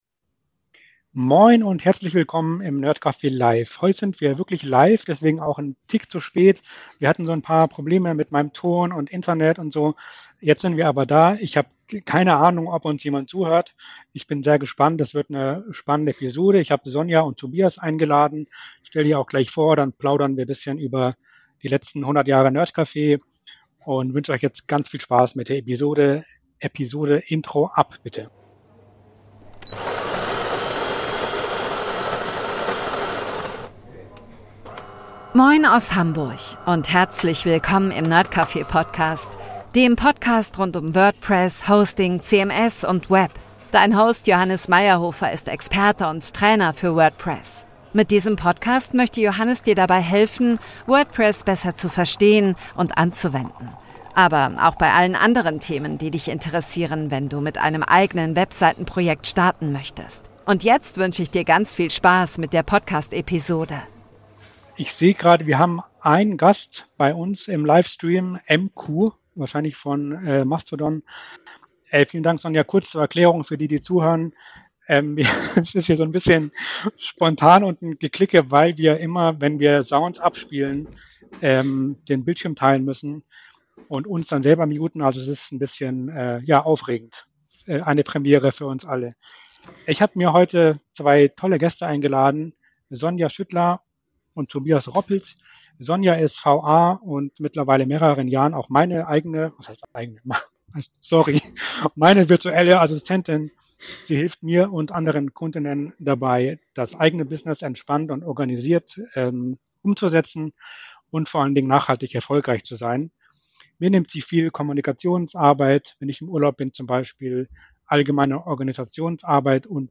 Wir haben deswegen heute gesprochen über: das nerdcafe, Podcasting allgemein, Partnerschaften, Zusammenarbeiten, Kund*innen-Akquise. Es kommen außerdem viel der Gäste zu Wort, denn einige haben mir ganz liebe Grüße per Sprachnachricht geschickt.
Der nerdcafe Podcast steht für ein offenes, vielfältiges und zugängliches Internet – verständlich erklärt, entspannt im Ton, aber mit Tiefgang.